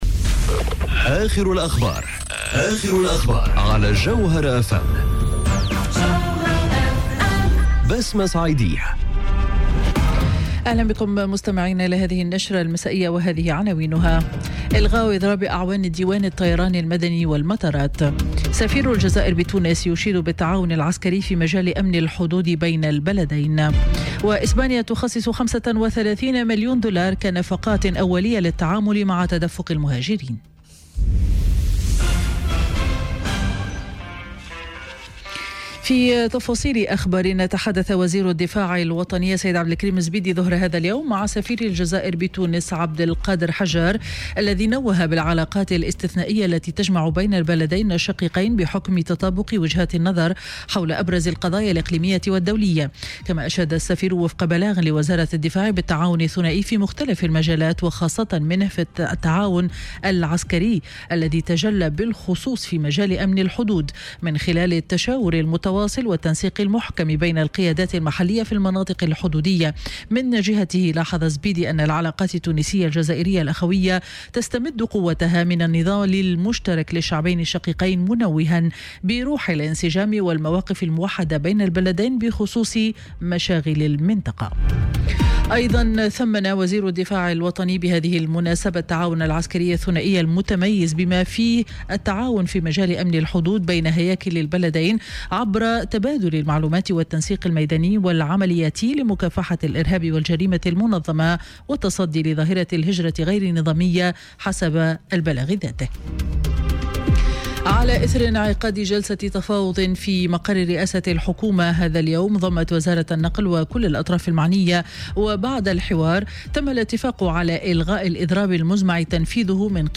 نشرة أخبار السابعة مساء ليوم الاثنين 30 جويلية 2018